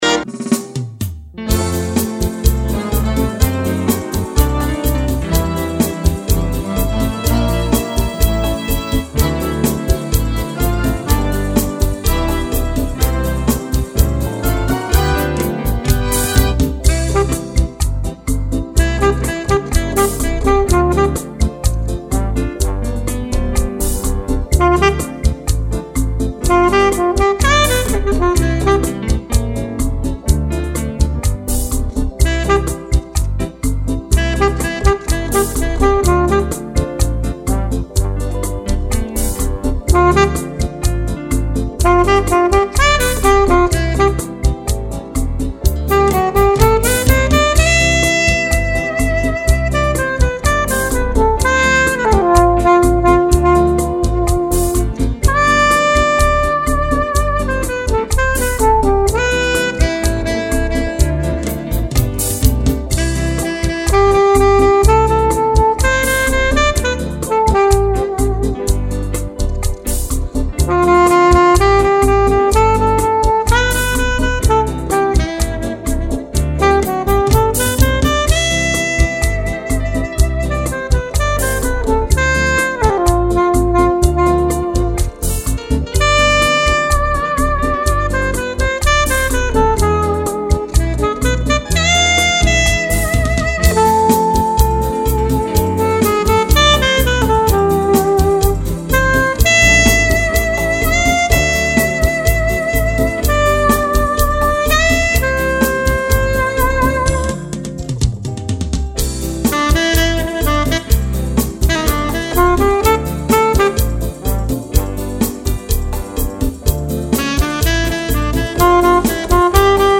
840   05:20:00   Faixa:     Bolero